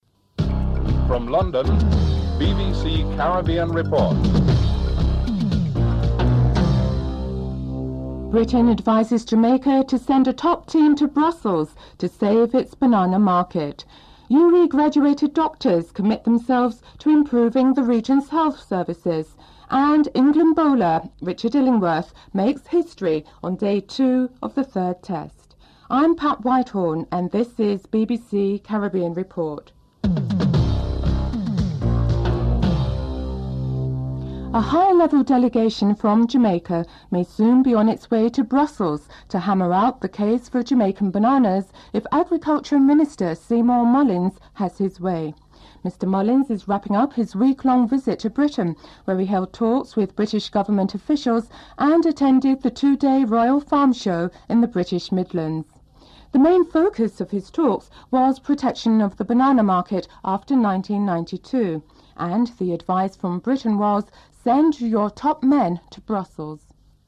1. Headlines (00:00-00:34)
Jamaica’s Agriculture Minister, Seymour Mullings is interviewed (00:35-03:01)
Jonathan Agnew reports from Trent Bridge (09:12-10:57)